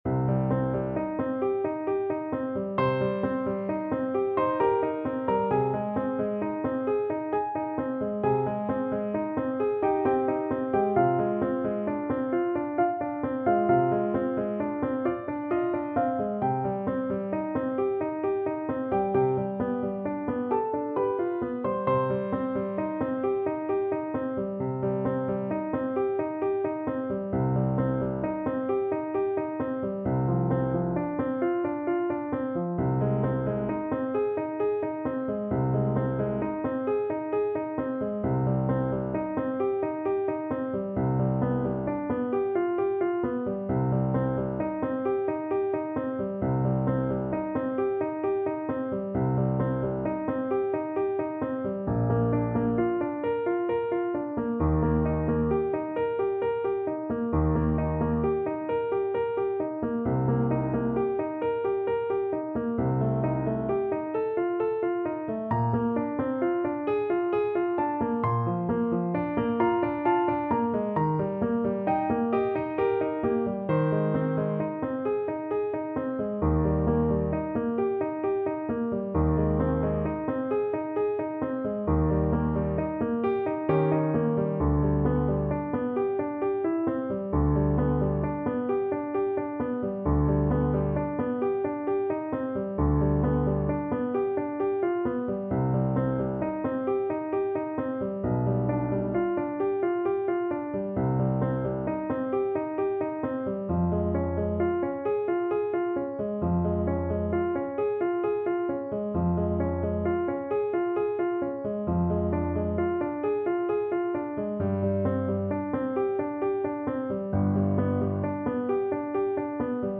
~ = 100 =66 Andante
3/4 (View more 3/4 Music)
Classical (View more Classical Soprano Voice Music)